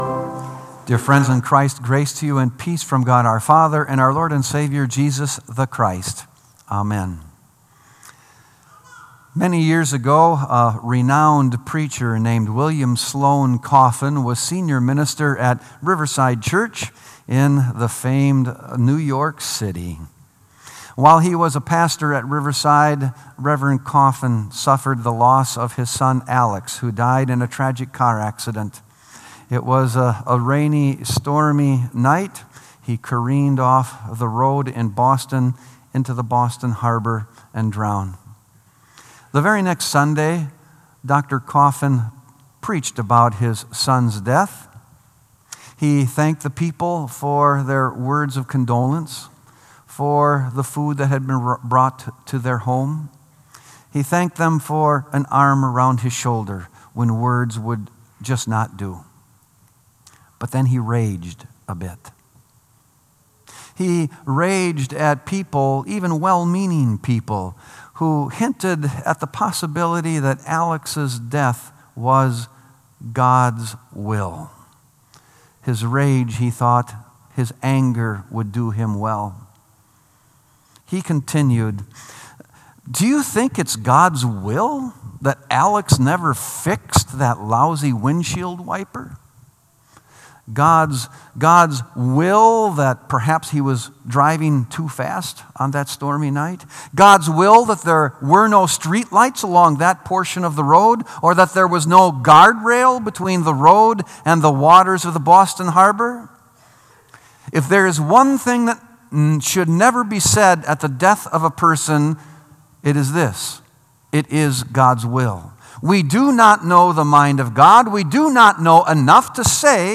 Sermon “The Urgent Jesus” | Bethel Lutheran Church
Sermon “The Urgent Jesus”